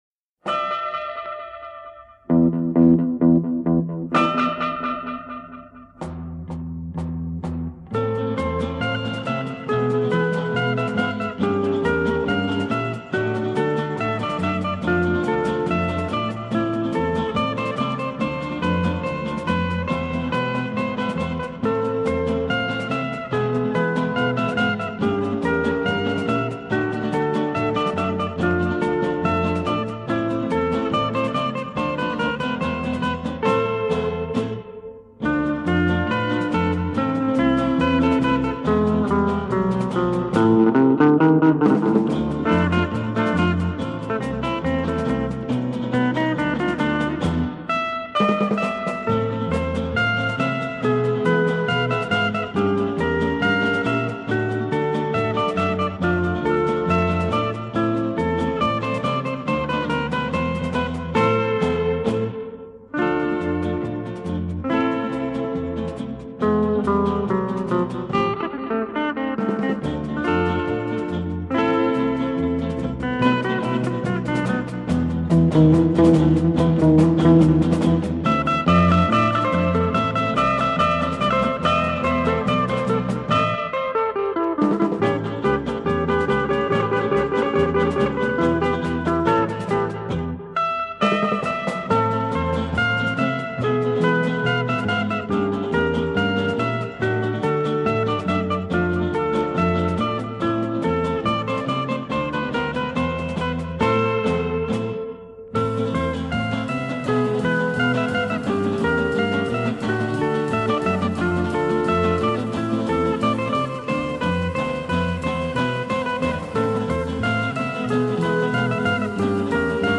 Жанр: Folk-Rock, Эстрада, Soft Rock